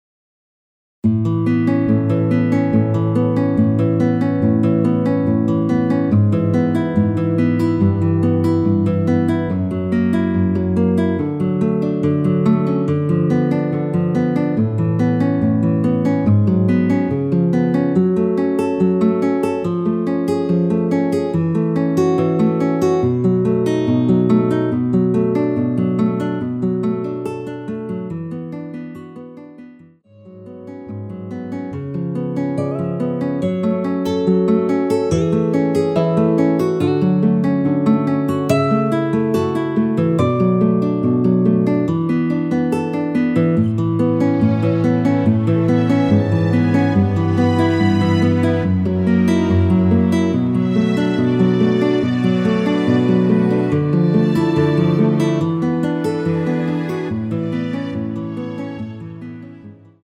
1절후 바로 후렴으로 진행 됩니다.(본문 가사및 미리듣기 확인 )
앞부분30초, 뒷부분30초씩 편집해서 올려 드리고 있습니다.
중간에 음이 끈어지고 다시 나오는 이유는